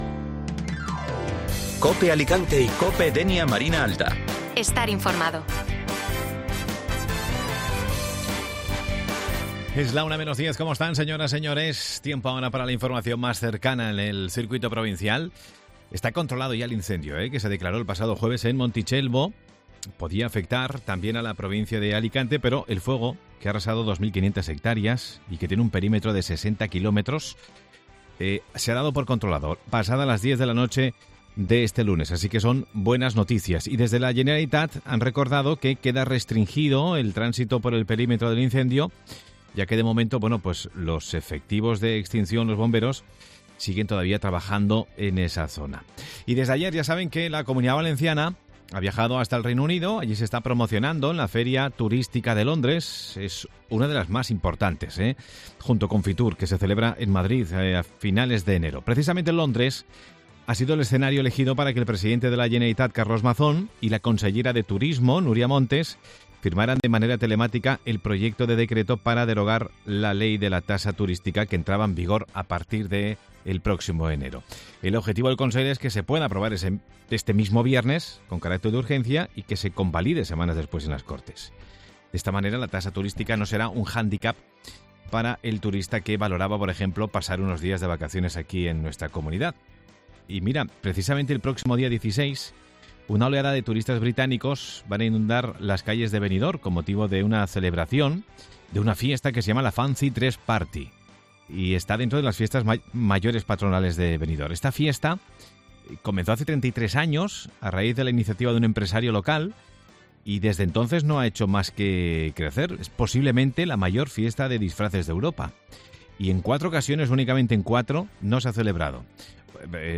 Programa de actualidad y noticias de la provincia de Alicante